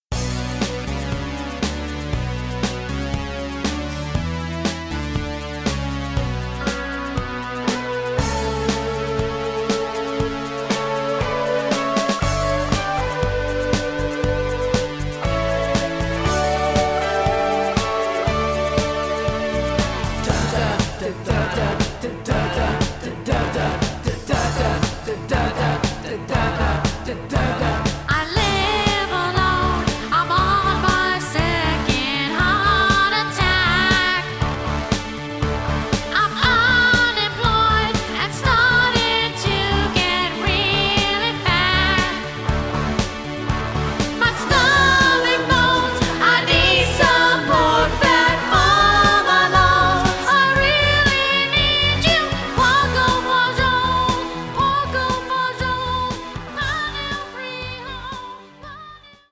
(CD - $12.95)--hilarious song parodies